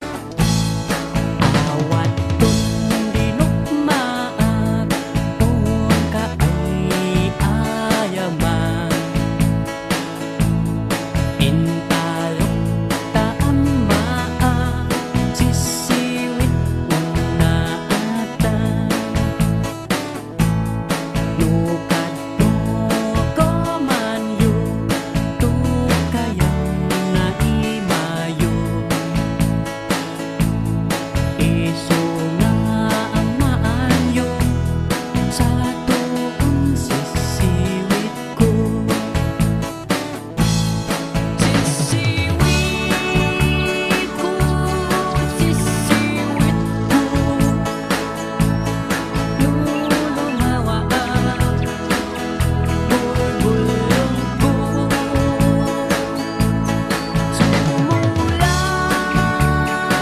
An Igorot/ Kalinga song